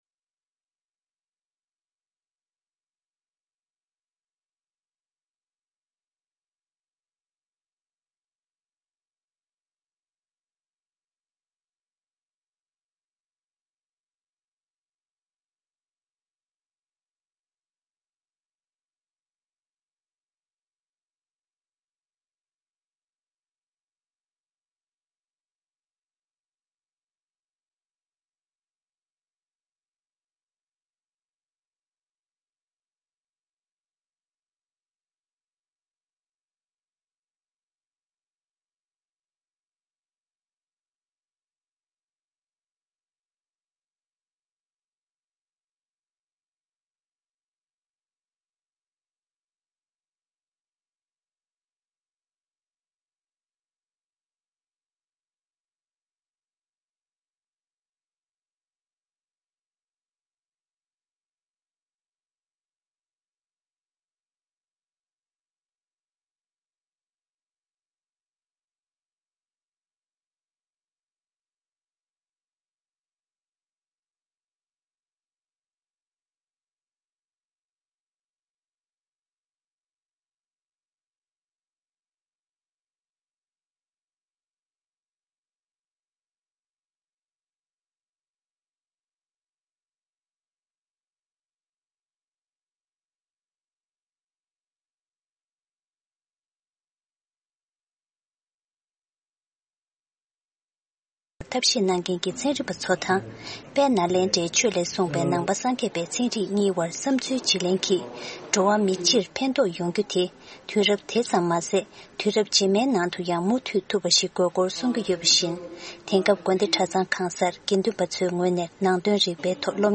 Evening News Broadcast daily at 10:00 PM Tibet time, the Evening Show presents the latest regional and world news, correspondent reports, and interviews with various newsmakers and on location informants.